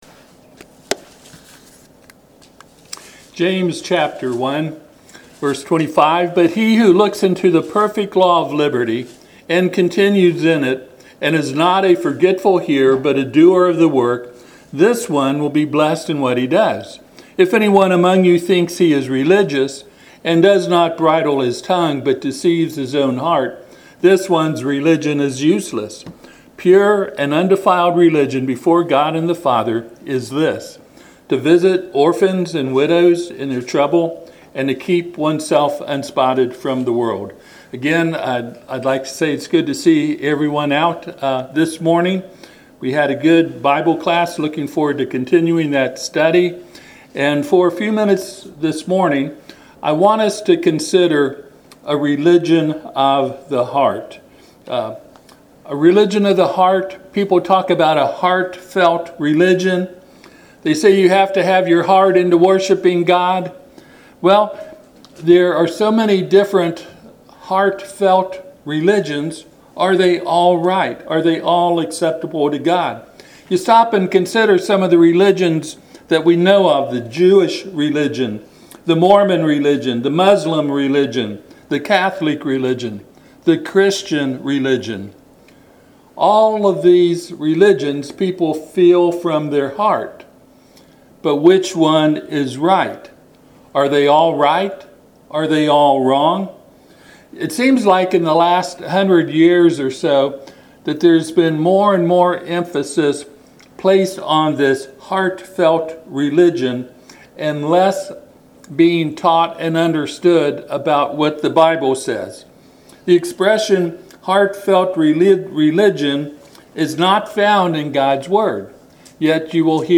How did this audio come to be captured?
Passage: James 1:25-27 Service Type: Sunday AM